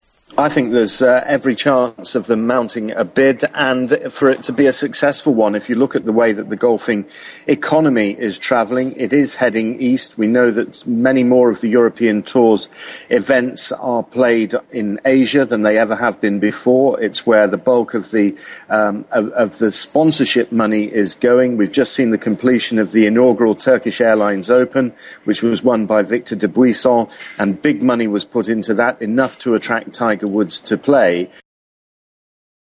【英音模仿秀】土耳其欲接手迪拜竞赛 争办2022年莱德杯 听力文件下载—在线英语听力室